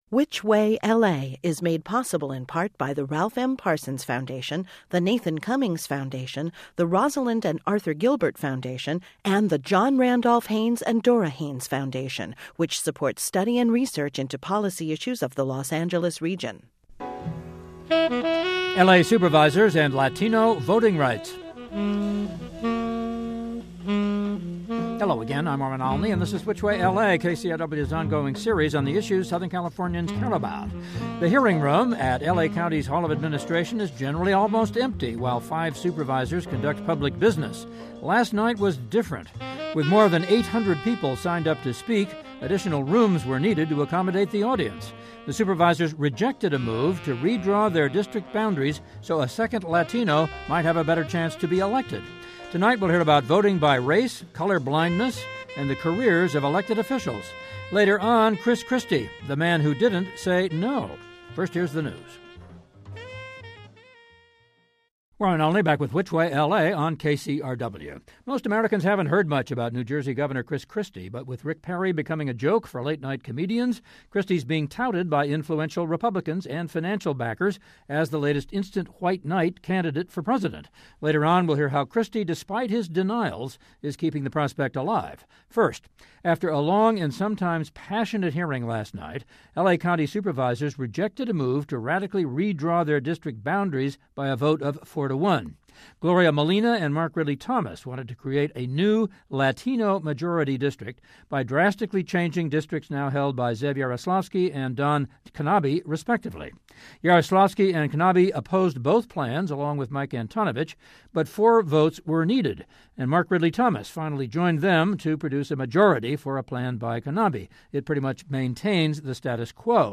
The Supervisors rejected a move to redraw their district boundaries so a second Latino might have a better chance to serve. We hear about voting by race, color blindness and the careers of elected officials. On our rebroadcast of today's To the Point, Chris Christie, the man who didn't say "no."